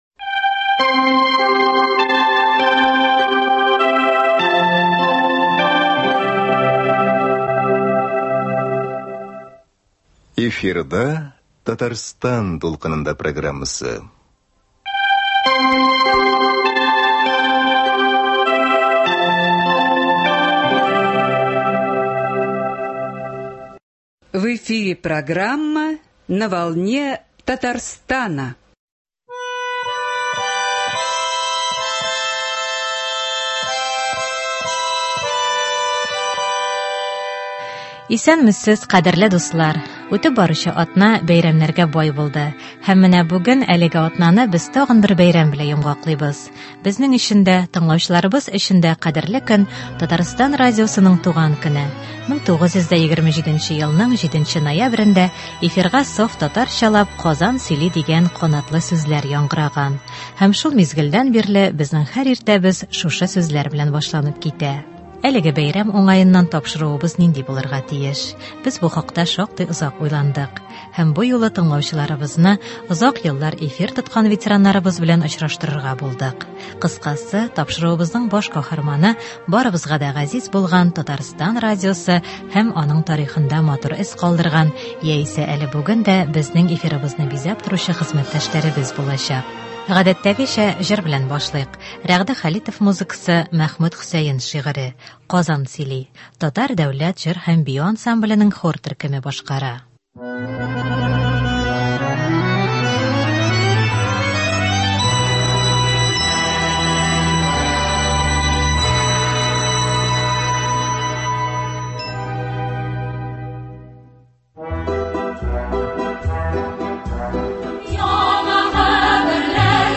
Бәйрәм уңаеннан студиягә популяр тапшырулар авторлары, озак еллар эшләп тыңлаучылар ихтирамын казанган журналистлар чакырылган иде.